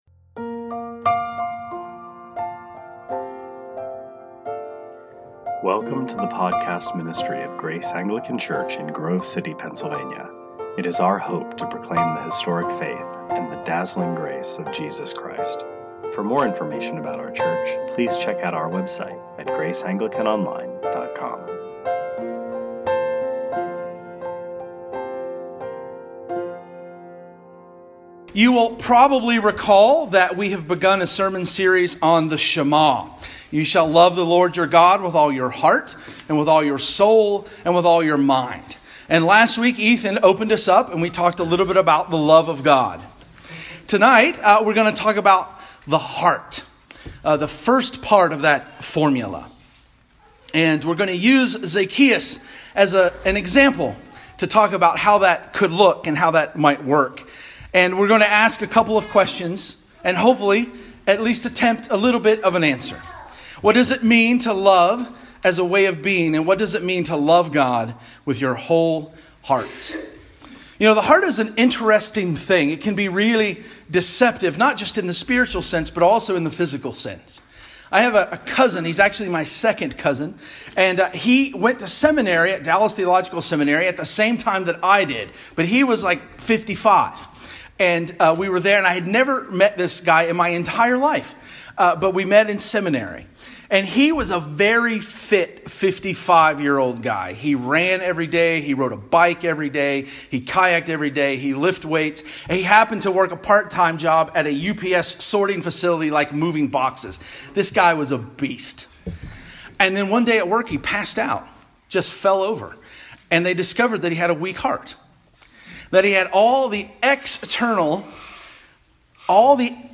2018 Sermons